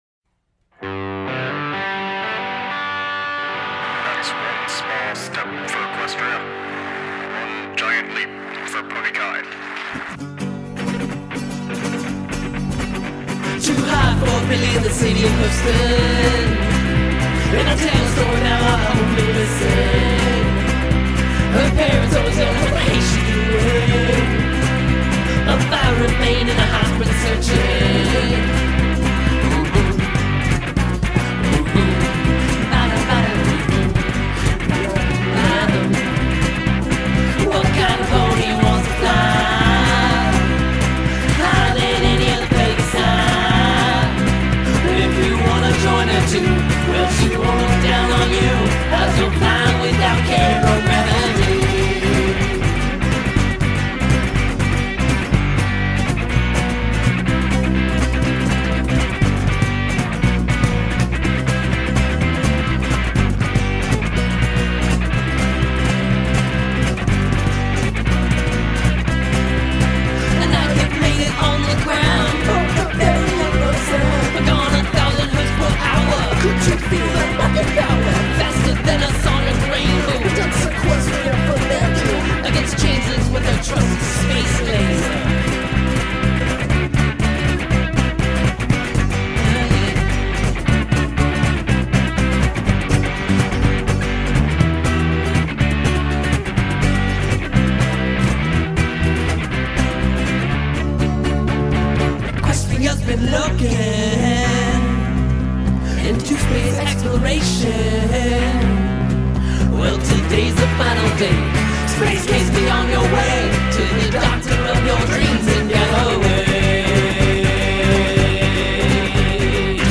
Acoustic Guitar, Electric Guitar, Electric Bass, Percussion
Vocals